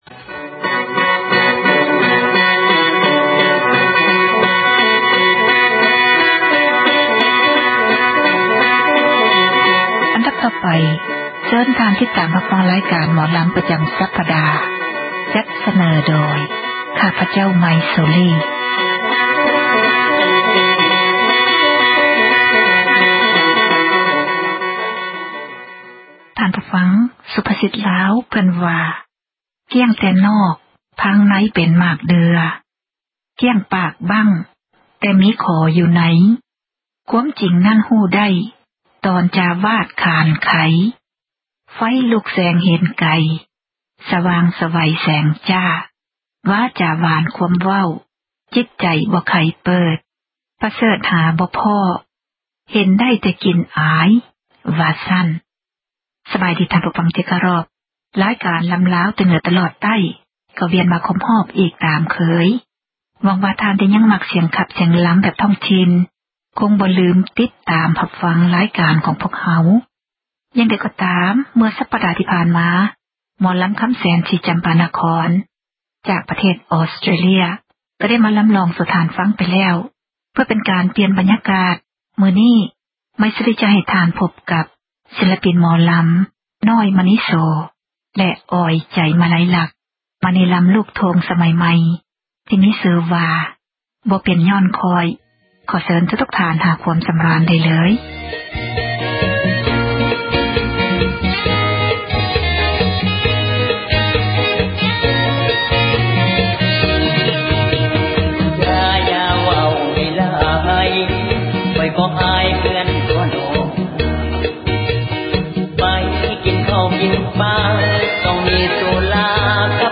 ຣາຍການ ໝໍລຳລາວ ປະຈຳ ສັປດາ ຈັດສເນີ ທ່ານ ໂດຍ